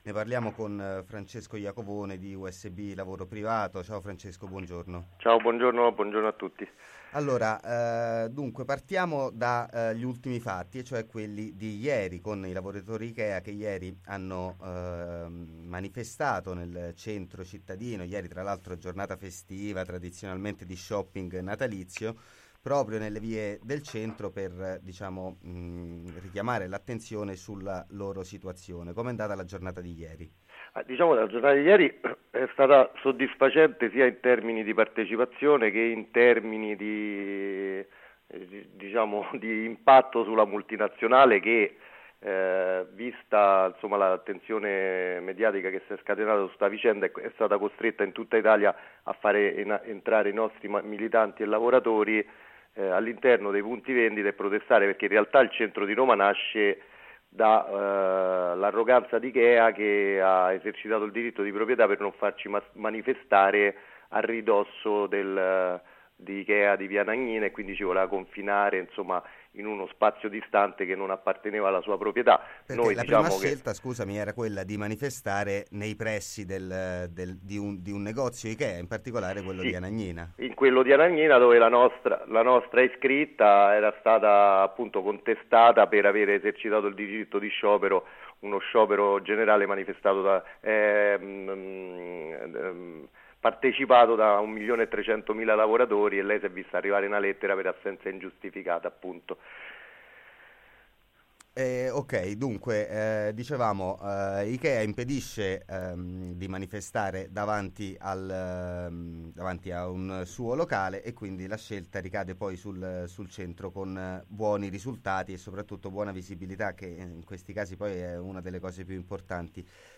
Manifestazione lavoratori Ikea